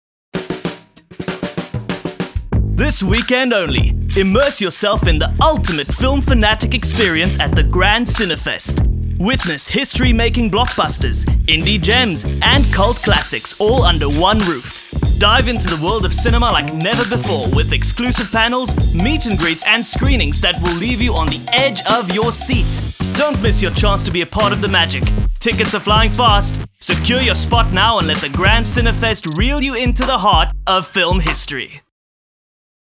character, clear, dramatic, switched on
My demo reels
Hard-Sell | Energetic & Exciting